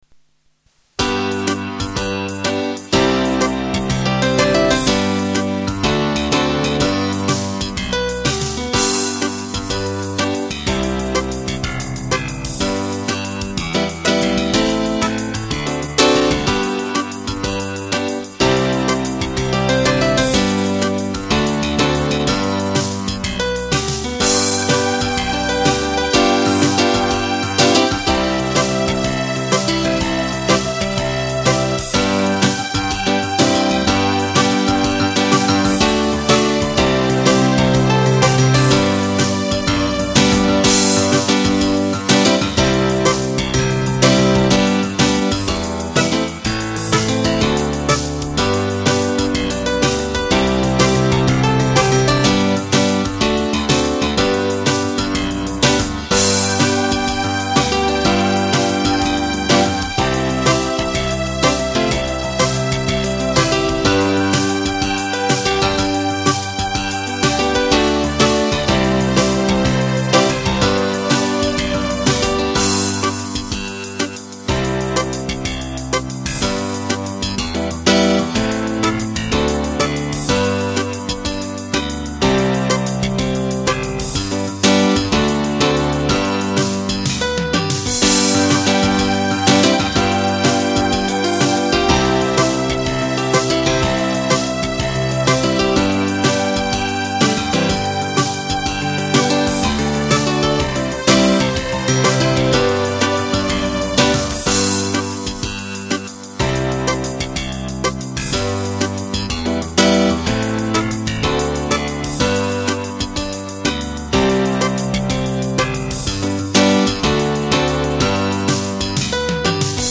PLAYBACKS